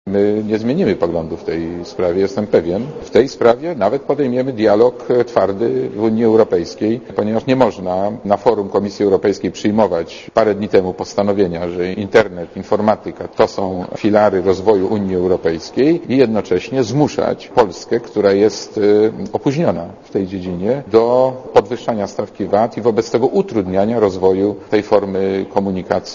Mowi marszałek Borowski (101 KB)